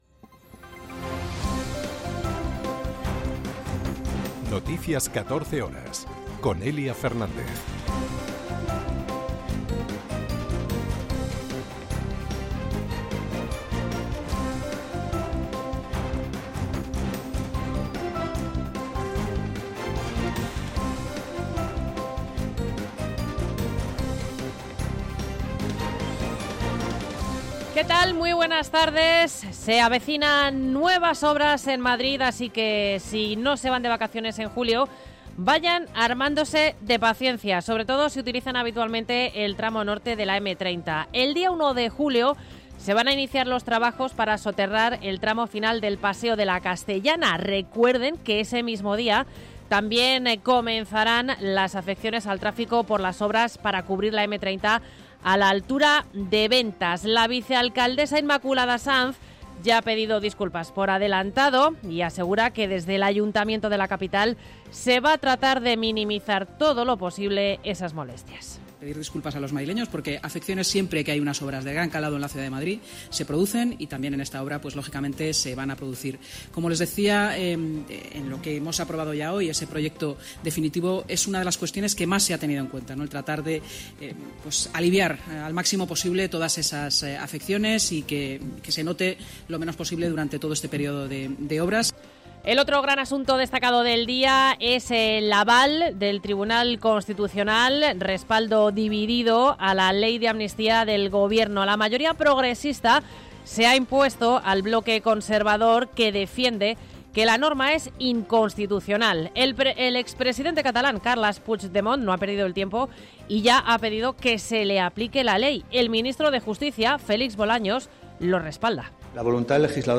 Noticias 14 horas 26.06.2025